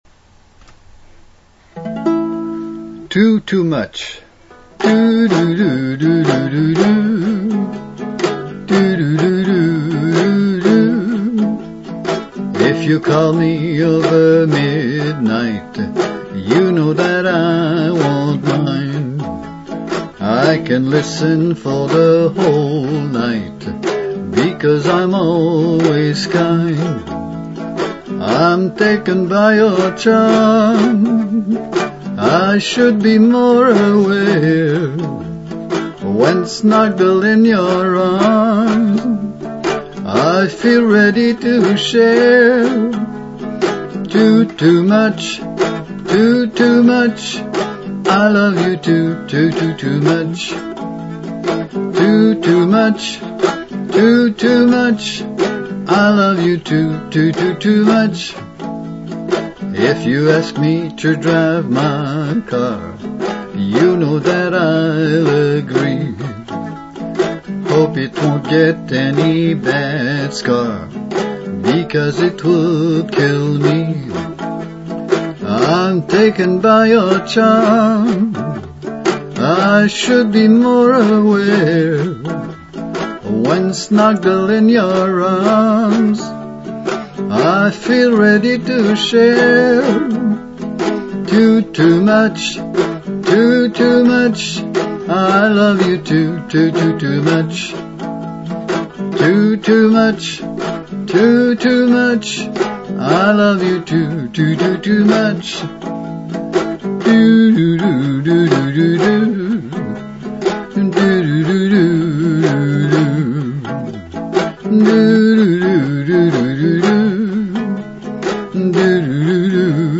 tootoomuchuke.mp3
Here is one of my songs.Rough demo: My voice + ukulele